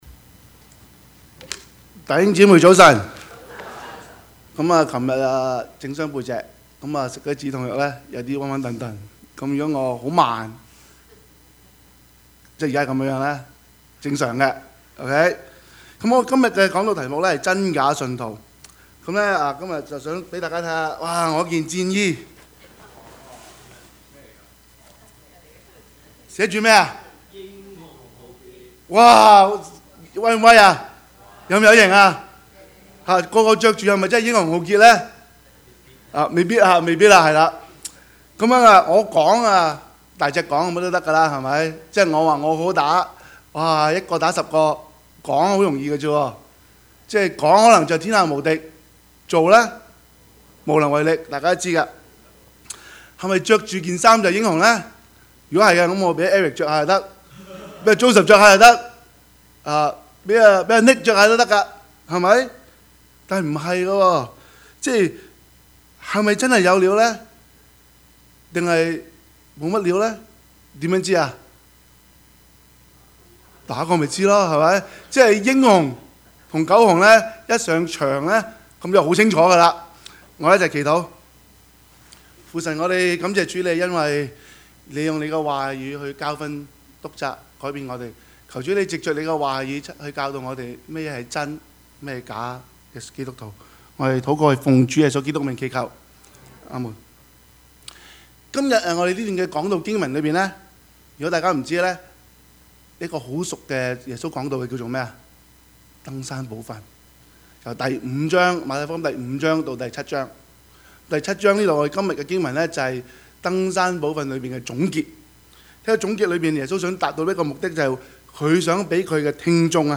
Service Type: 主日崇拜
Topics: 主日證道 « 一家兩制 等候 »